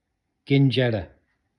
10. кинджэре